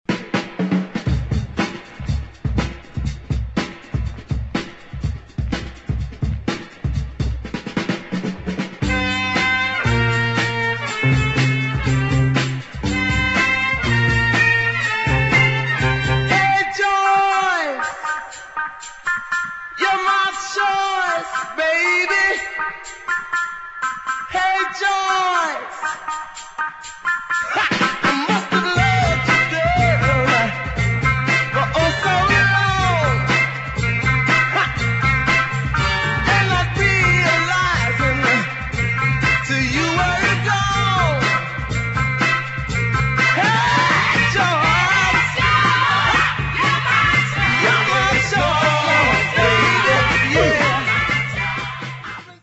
[ JAZZ / FUNK / DOWNBEAT ]